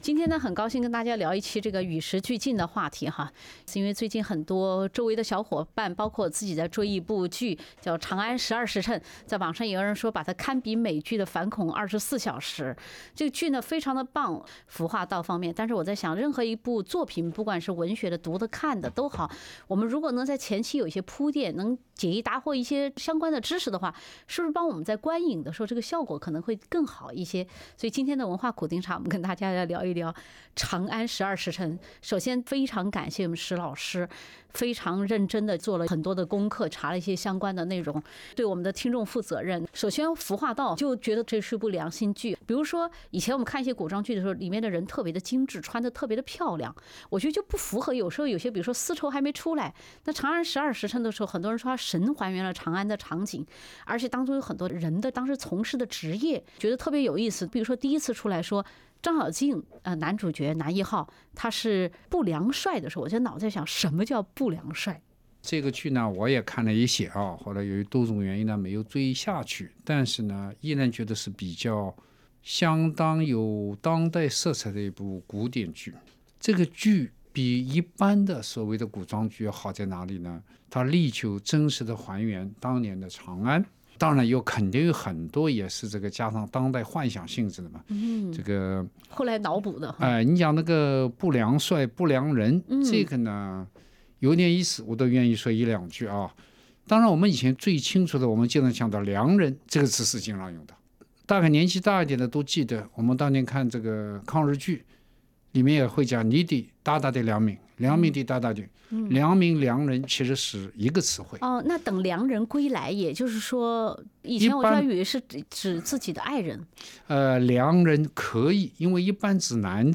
欢迎收听SBS 文化时评栏目《文化苦丁茶》，本期话题是： 解码长安十二时辰（全集）。